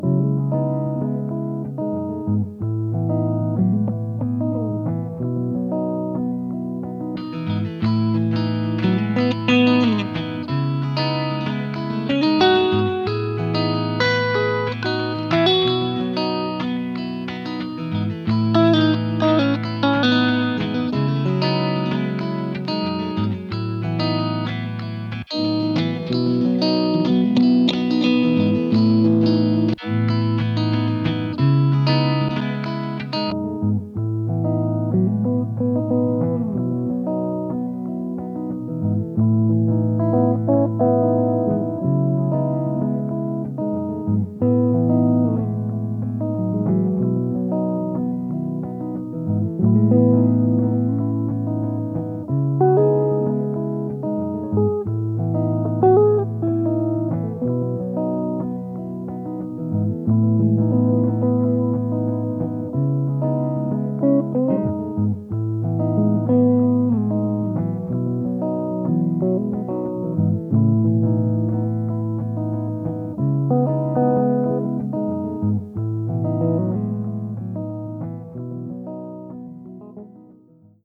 Loop Reprise (covered)